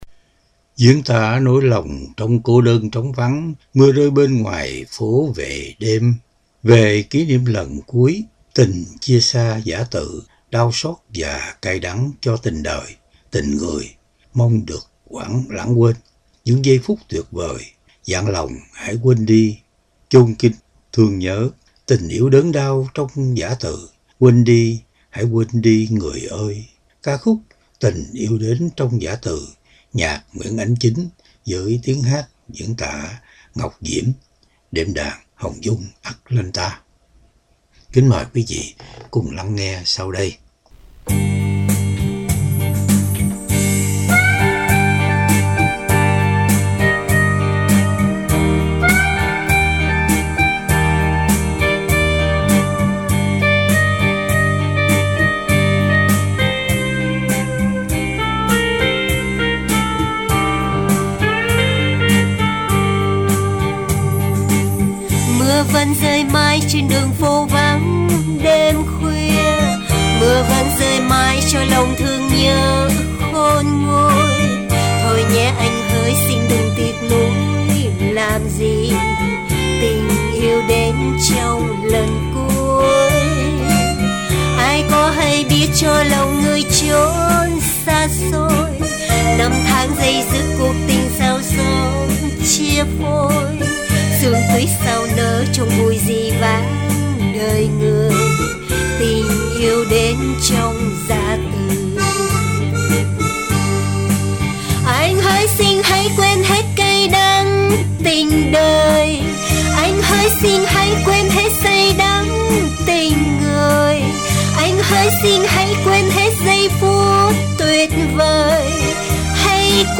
Đệm Đàn